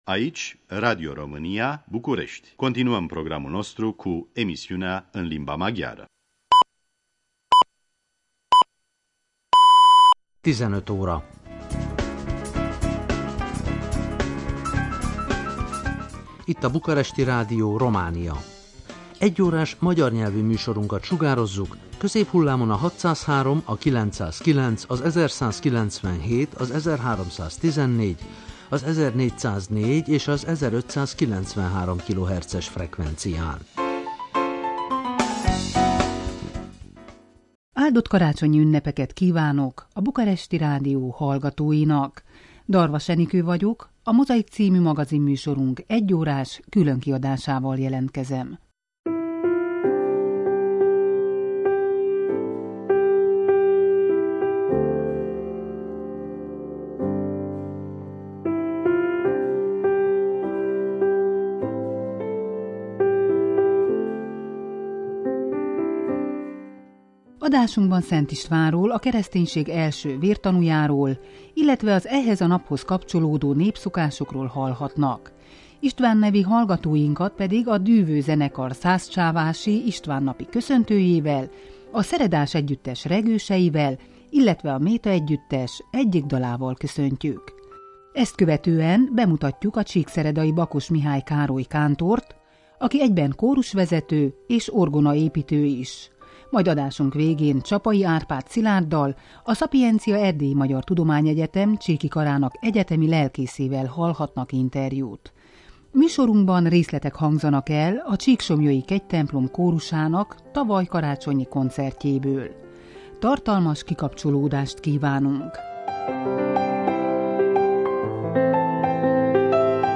Műsorunkban részletek hangzanak el a csíksomlyói kegytemplom kórusának tavalyi karácsonyi koncertjéből.